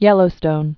(yĕlō-stōn)